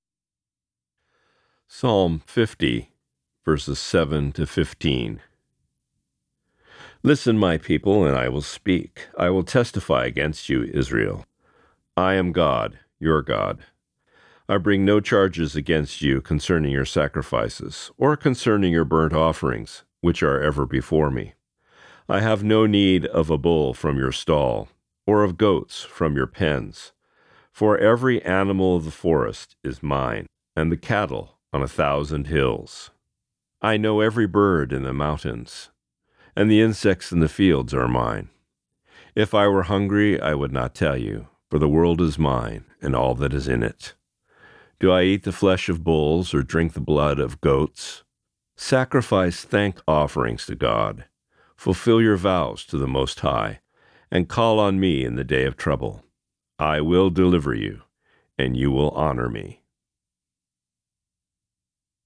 Reading: Psalm 50:7-15